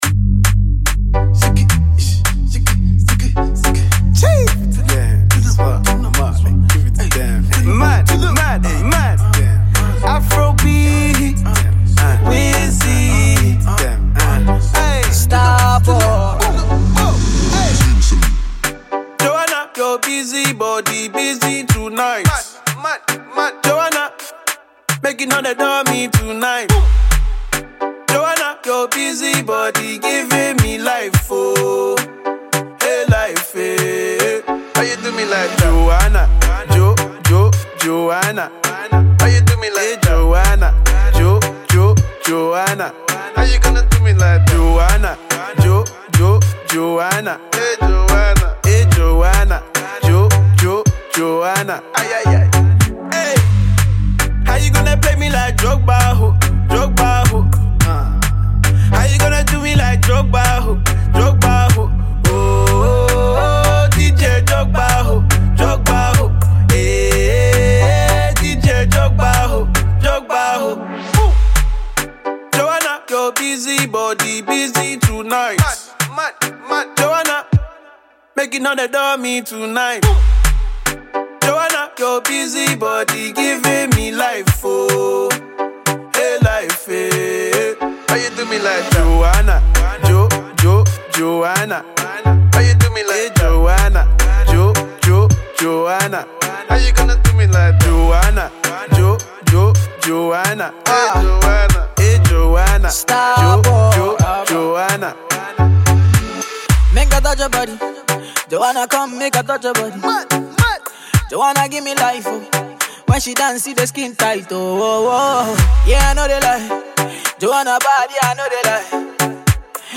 sweetly melodic